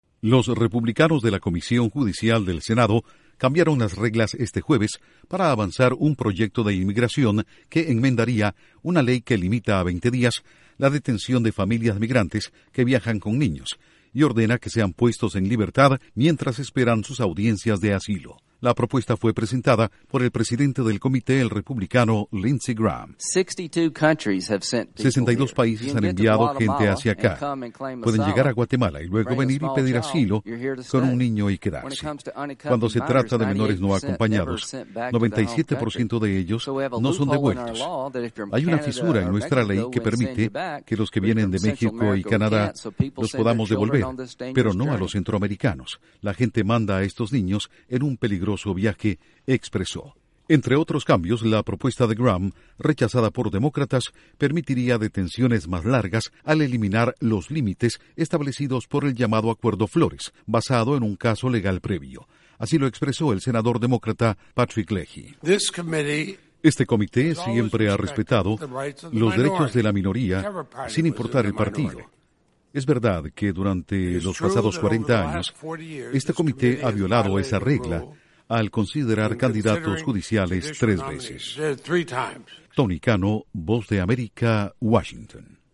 Duración: 1:25 1 audio de Lindsey Graham/Senador Republicano 1 audio de Patrick Leahy/ Senador Demócrata